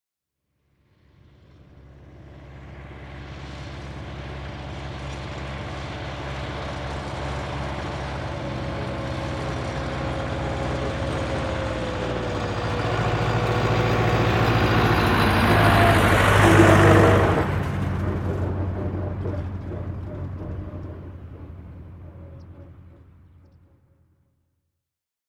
دانلود آهنگ تراکتور 12 از افکت صوتی حمل و نقل
جلوه های صوتی
دانلود صدای تراکتور 12 از ساعد نیوز با لینک مستقیم و کیفیت بالا